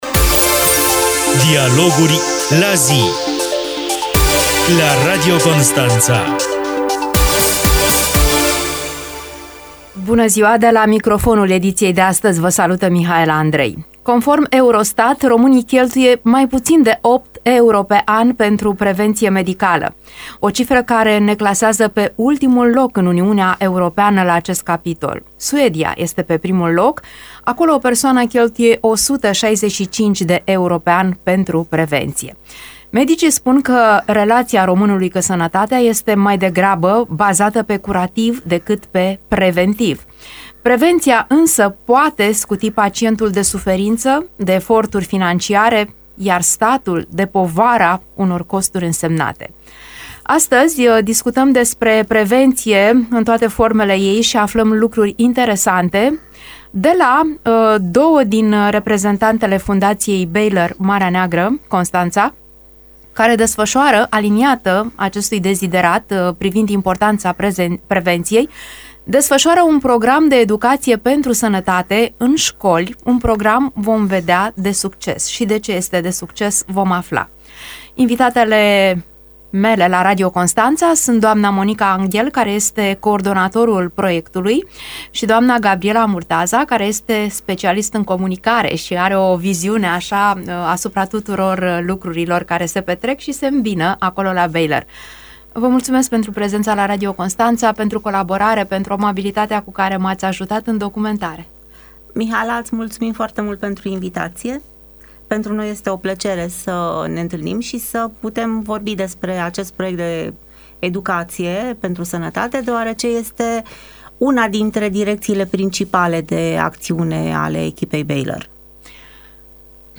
Iată un secvență din acest dialog.